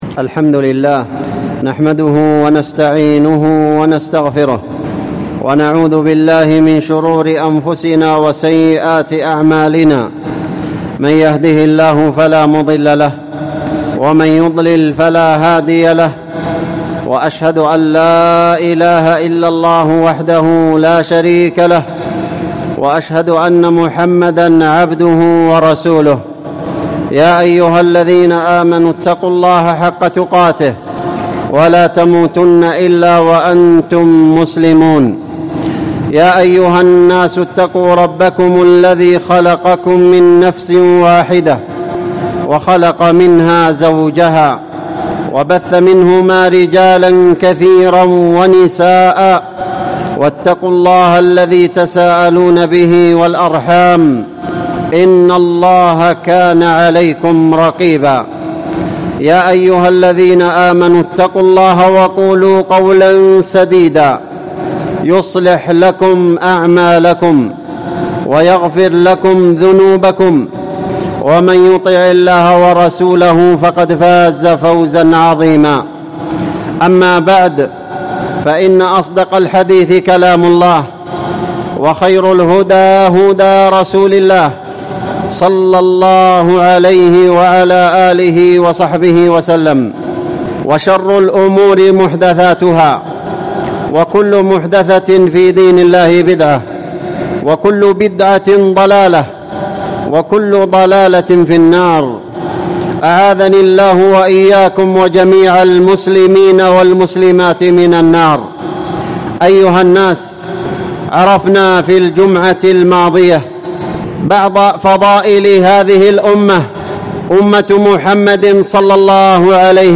وبل السحابة في فضائل الصحابة | الخطب والمحاضرات